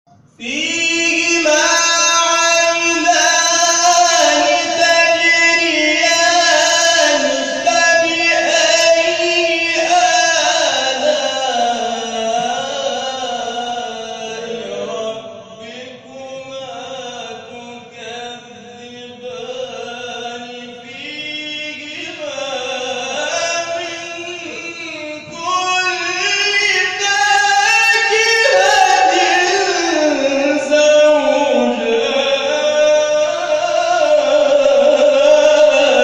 گروه شبکه اجتماعی: نغمات صوتی از تلاوت قاریان ممتاز و بین‌المللی کشور را می‌شنوید.